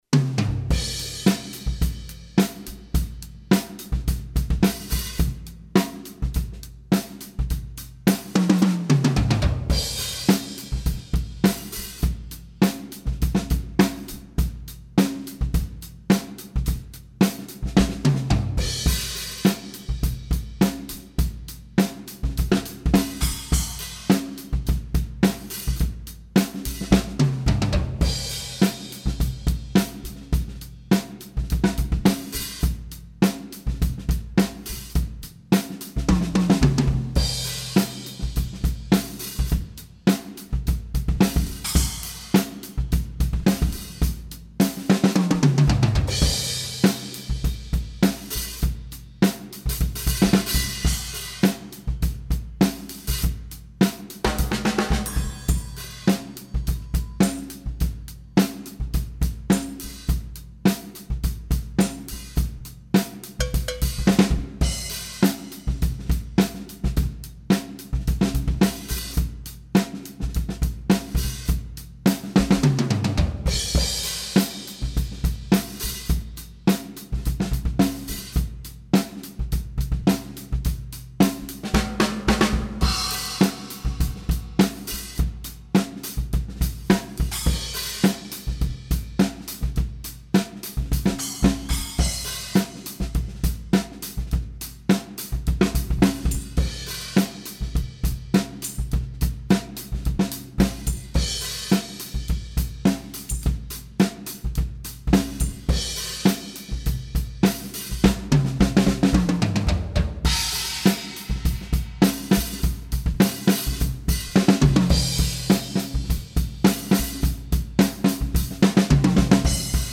trommelte in Rödelheim